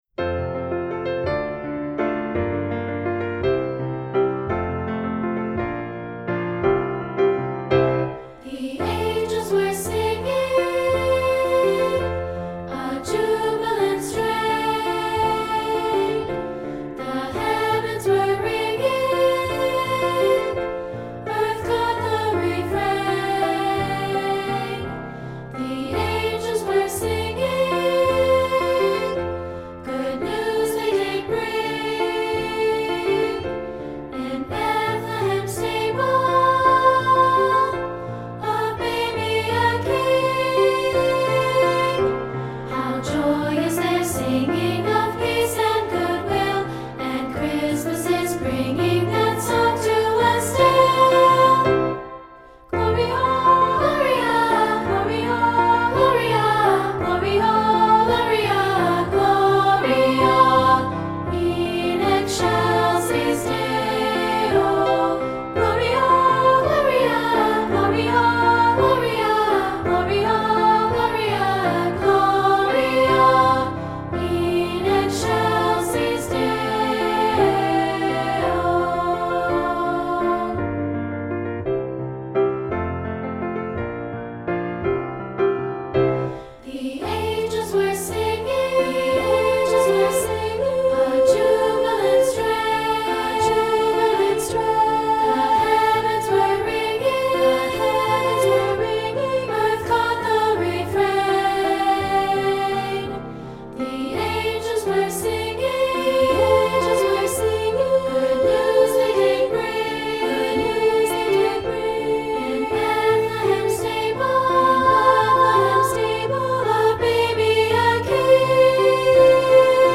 Voicing: Two-part